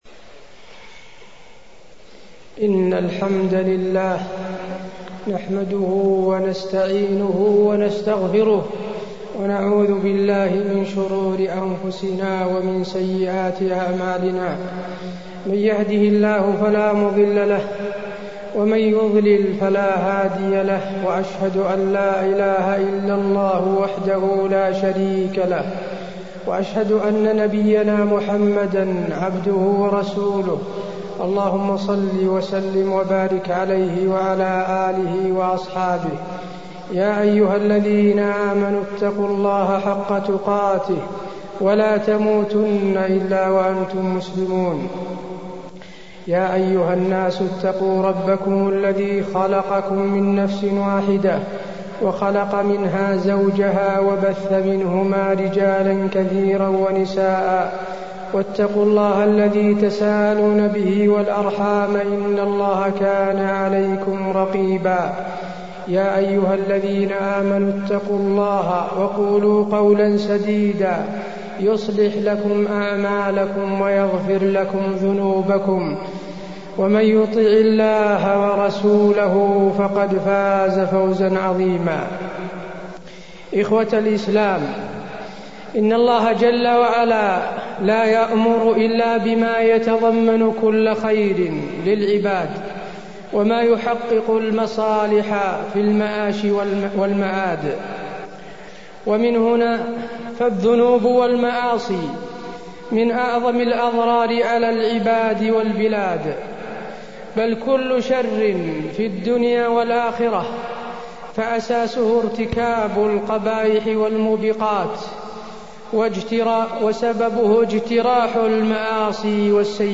تاريخ النشر ٣٠ شوال ١٤٢٣ هـ المكان: المسجد النبوي الشيخ: فضيلة الشيخ د. حسين بن عبدالعزيز آل الشيخ فضيلة الشيخ د. حسين بن عبدالعزيز آل الشيخ أثر المعاصي والذنوب